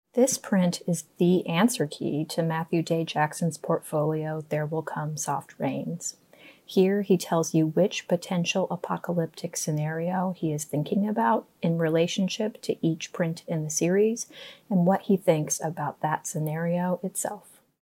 Listen to a curator talk about this work.